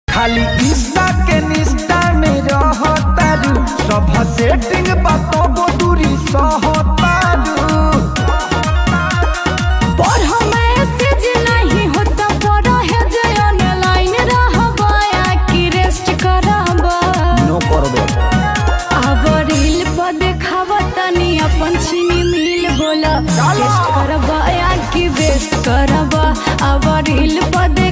new bhojpuri